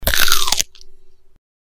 crunch-sound-effect.mp3